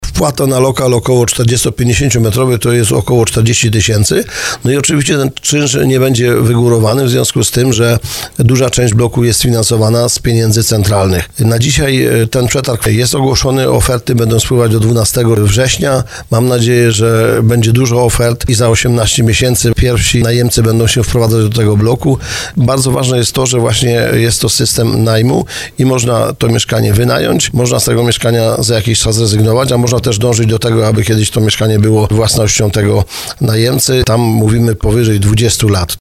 Burmistrz Krzysztof Kaczmarski poinformował w programie Słowo za Słowo, że ruszył przetarg na realizację inwestycji w ramach Społecznej Inicjatywy Mieszkaniowej. Podkreślił, że jest wiele chętnych osób, bo i warunki są bardzo korzystne.